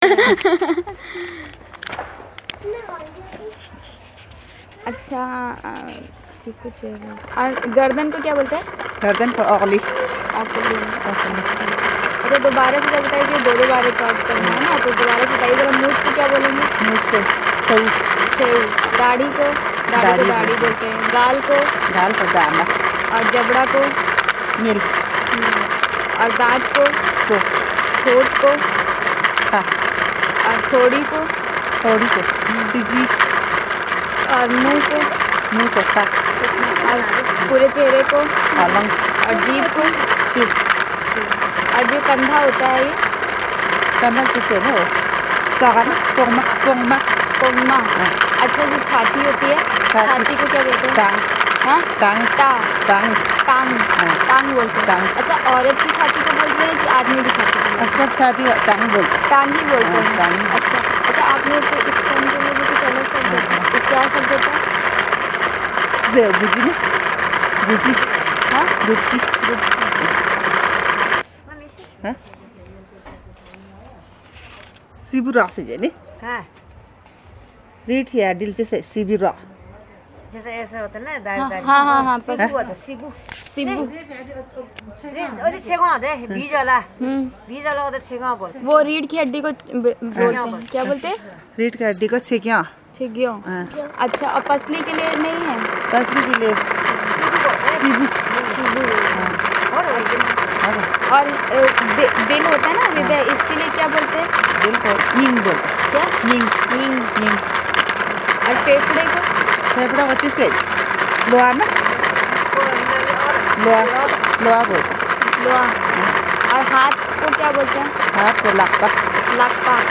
Elicitation of words about human body parts and kinship terms